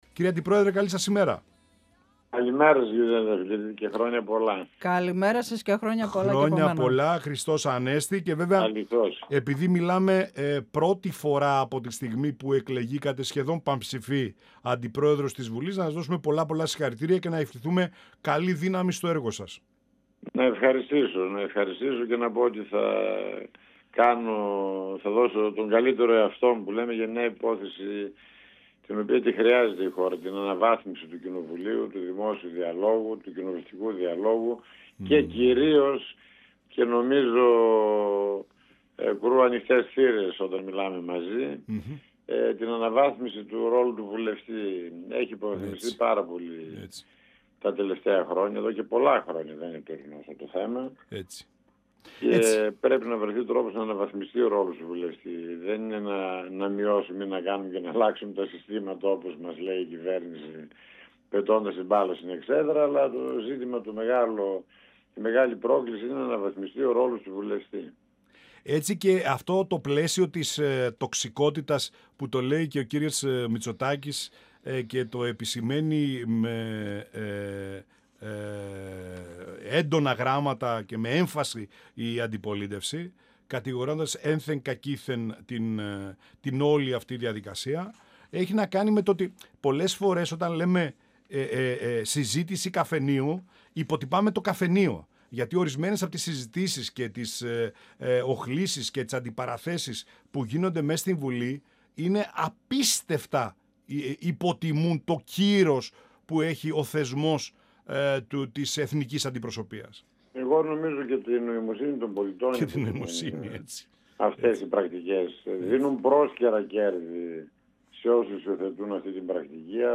Στο θέμα της άρσεως ασυλίας των βουλευτών της ΝΔ για τοσκάνδαλο του ΟΠΕΚΕΠΕ αλλά και το θέμα Λαζαρίδη και τιςπαρενέργειές του αναφέρθηκε ο Αντιπρόεδρος της Βουλής καιΒουλευτής Κοζάνης του ΠΑΣΟΚ-ΚΙΝΑΛ Πάρης Κουκουλόπουλος ,μιλώντας στην εκπομπή «Πανόραμα Επικαιρότητας» του 102FM τηςΕΡΤ3.
Συνεντεύξεις